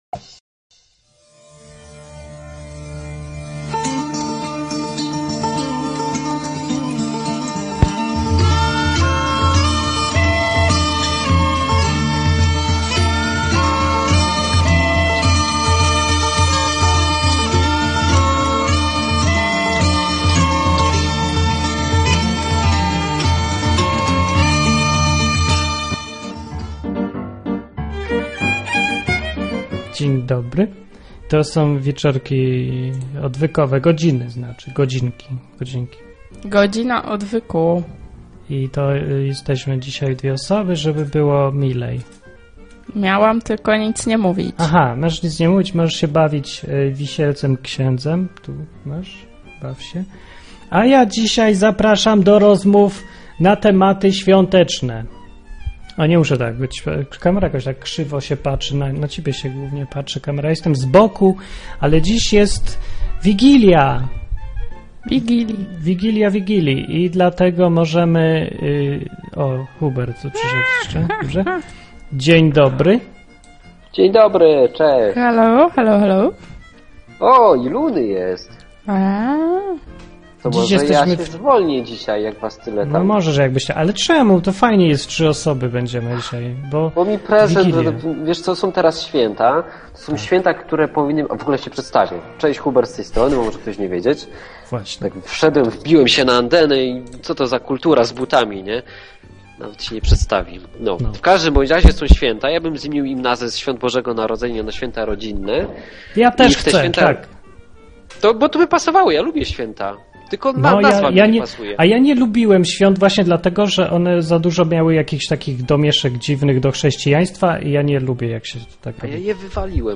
Rozmowy ze słuchaczami na tematy Biblii, Boga, księdza, egzorcyzmów.